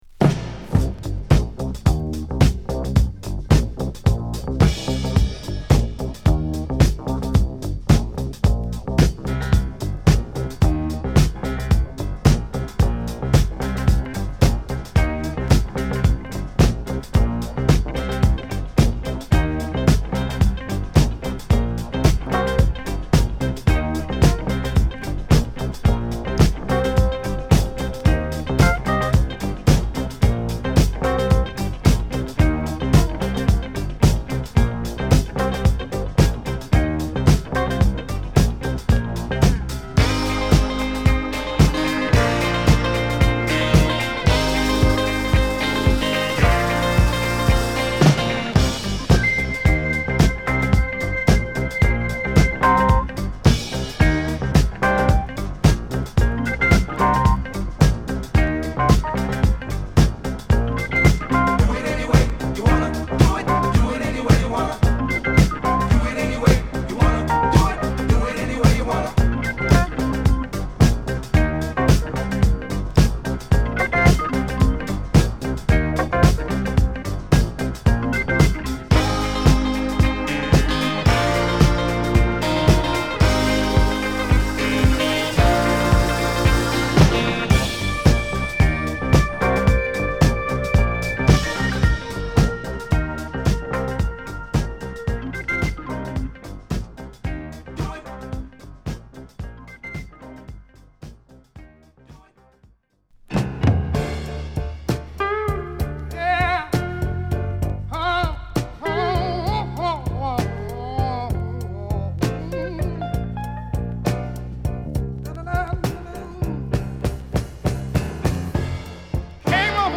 フィラデルフィアのディスコグループ